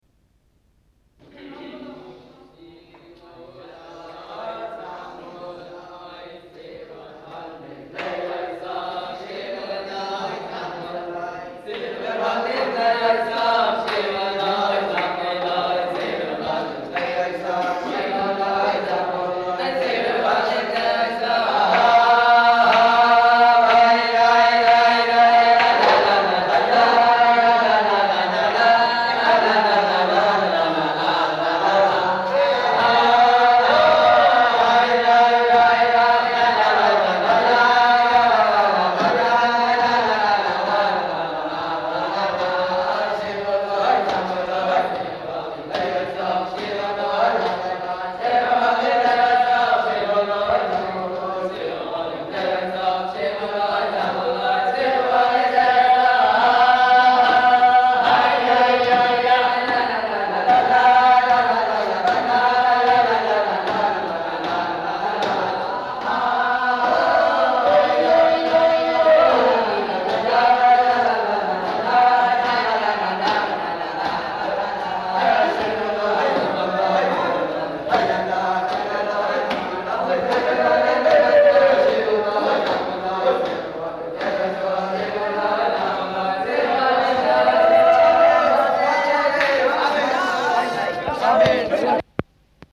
Dance tune (Niggun rikud)
Hassidic dance tunes have defined musical characteristics such as duple meter and fast tempi (metronome 116-168).
AB structure dance tune
Shiru Lo Zimro Lo: bisectional dance tune. Performers: Yeshiva students.
Bnei Brak, Israel: 7.1.1967. NSA Y1037.